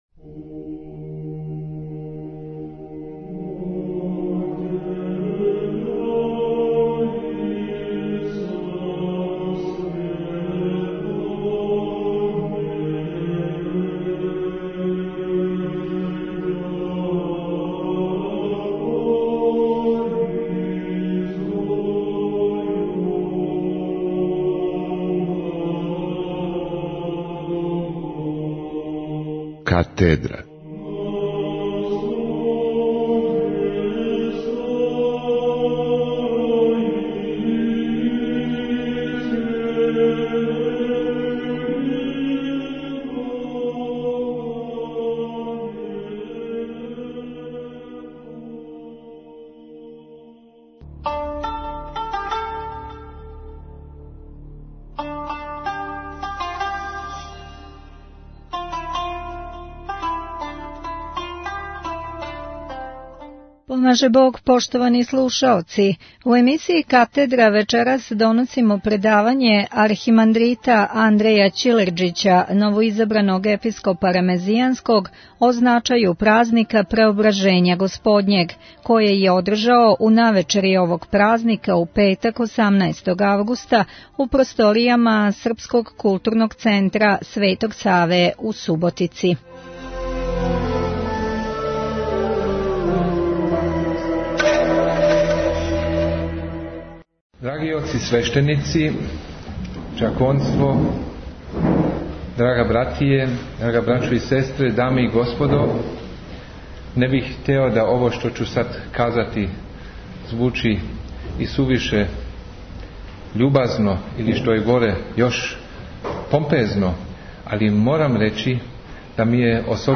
Запис са устоличења Његовог Преосвештенства Епископа Нишког Г. Јована (Пурића)
Запис са устоличења Његовог Преосвештенства Епископа Нишког Г. Јована (Пурића) у древну апостолску Катедру Нишке Епископије, након кога су, за трпезом љубави Преосвећеног Владику Нишког Јована благословили Његова Светост Патријарх Српски Г. Иринеј, Његово Високопреосвештенство Архиепископ Цетињски Митрополит Црногорско - приморски Г. Амфилохије, Његово Преосвештенство умировљени Епископ Захумско - херцеговачки Г. Атанасије (Јевтић) а на благословима и љубави Свјатејшем Патријарху и Епископима захвалио Преосвећени Владика Нишки Г. Јован (Пурић).